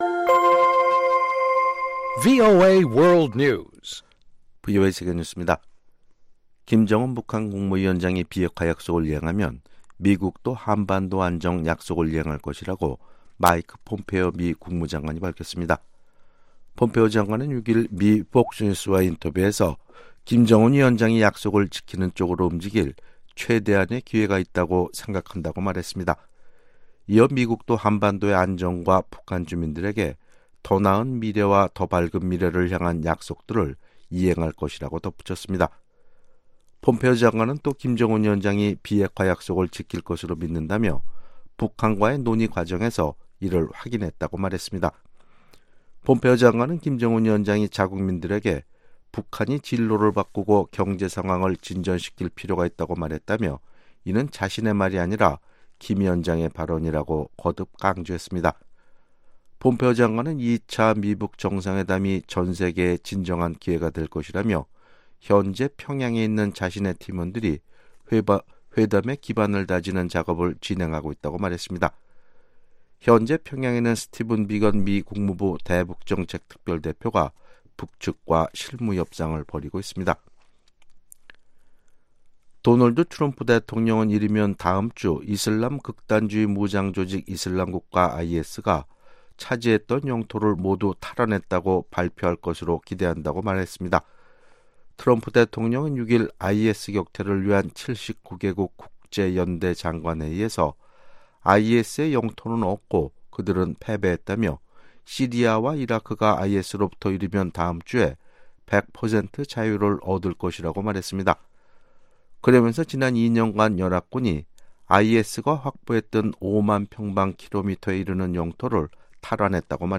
VOA 한국어 아침 뉴스 프로그램 '워싱턴 뉴스 광장' 2019년 2월 8일 방송입니다. 마이크 폼페오 미국 국무장관은 김정은위원장이 나라의 진로 전환과 경제발전 필요를 직접 말했다며, 북한의 비핵화 의지를 확인했다고 강조했습니다. 미국의 전문가들은 올해 트럼프 대통령의 국정연설은 2차 미-북 정상회담을 고려한 외교적 발언에 그쳤다고 평가했습니다.